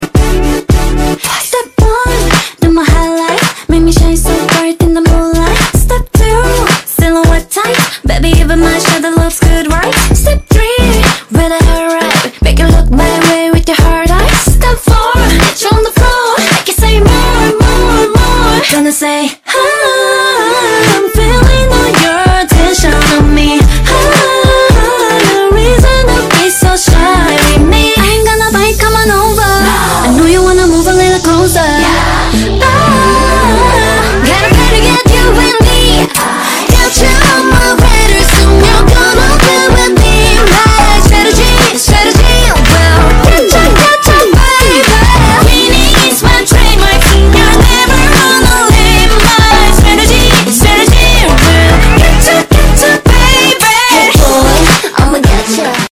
soy yo o se escucha muy saturado 😭
Se escucha un poco saturado pero está hermoso igual